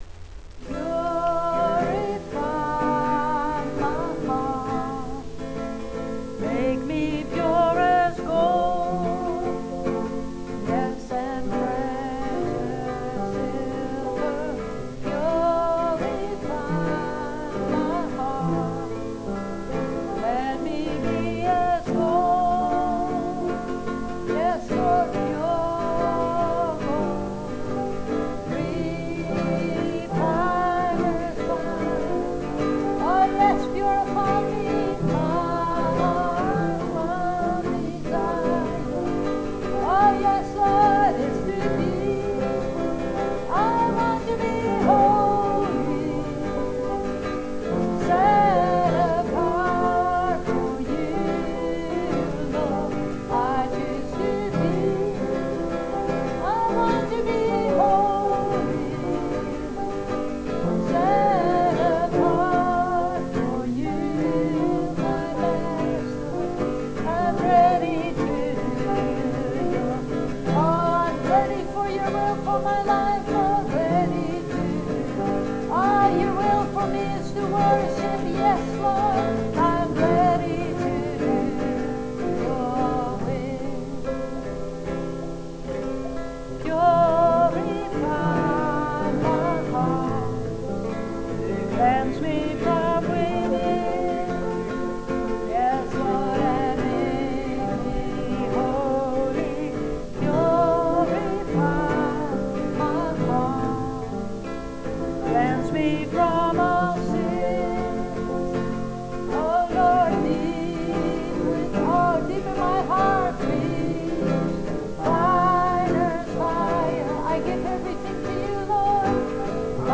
Worship - 12 - Dwelling Place Open Heaven Worship Center & Open Heaven RHEMA Application School
Worship-12.wav